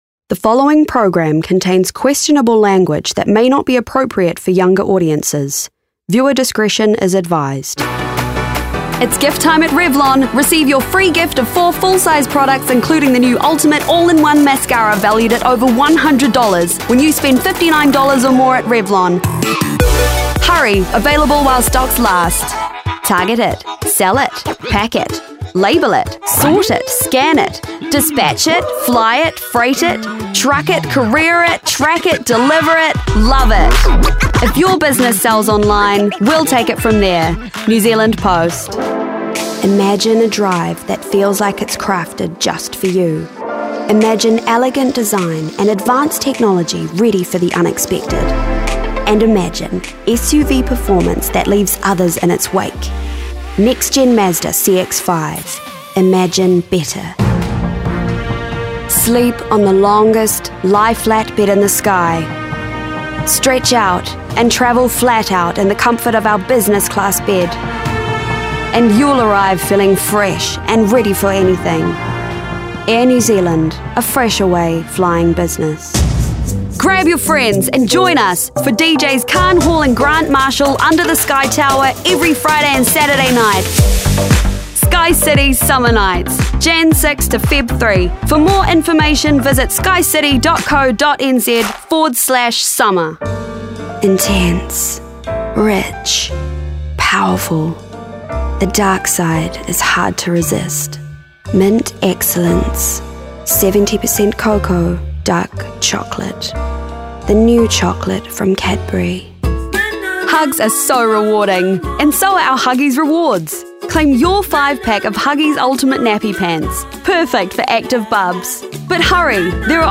Demo
Young Adult, Adult
new zealand | natural
COMMERCIAL 💸
smooth/sophisticated
warm/friendly
husky